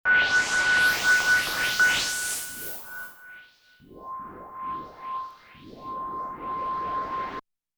Jupiter Sweep.wav